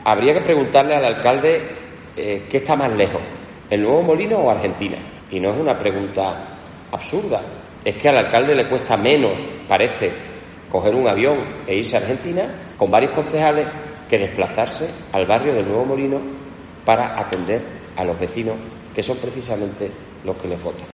Jaime Pérez, portavoz del Grupo Popular en el Ayto Huelva
El portavoz del PP en el Ayuntamiento de Huelva, Jaime Pérez, ha comparecido en rueda de prensa acompañado por el concejal del grupo municipal Francisco Millán para solicitar medidas y soluciones al Gobierno de Cruz ante los problemas de inseguridad, suciedad y mantenimiento que sufren los vecinos de la Avenida Costa de la Luz.